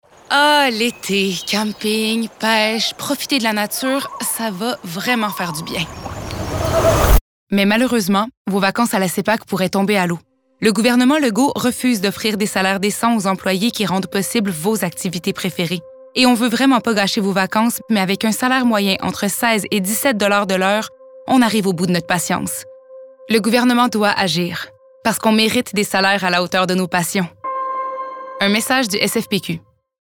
Le conflit de travail à la SEPAQ pourrait-il perturber vos projets de vacances ? Le Devoir Publicité radio Manifestation Sépaq devant le bureau de la ministre Charest Radio-Canada Téléjournal Estrie Noovo info Le Soleil Le Granby Express Débat : avons-nous trop de fonctionnaires?
vacances_en_peril_radio.mp3